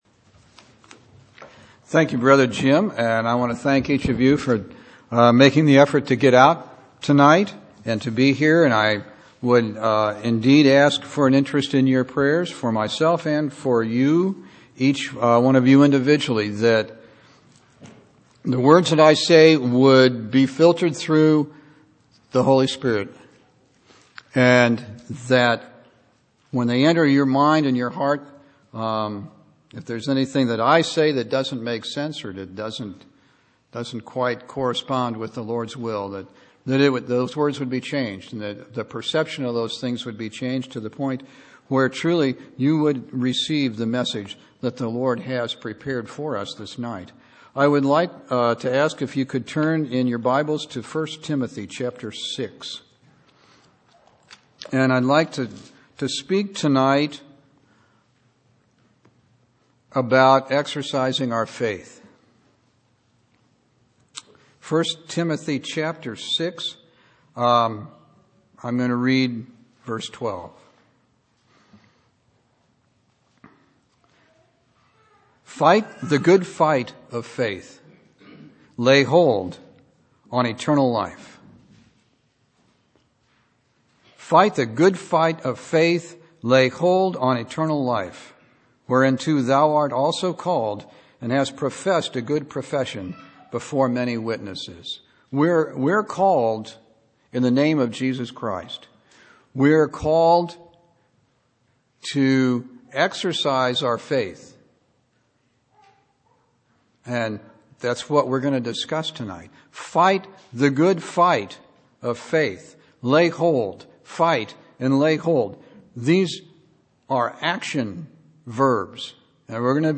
Verses that contain action verbs show us what we should be doing and what our actions should be if we are to ‘Fight the Good Fight of Faith.’ A good sermon for those of us with new Bibles so that we can highlight each of these wonderful scriptures.